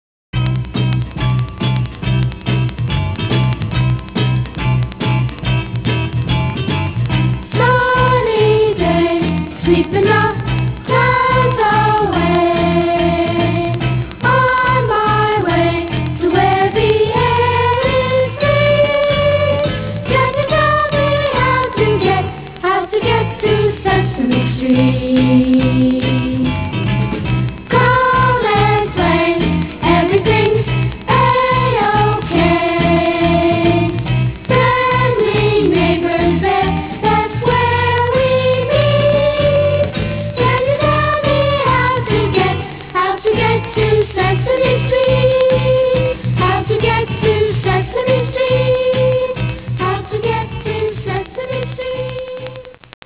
TV Themes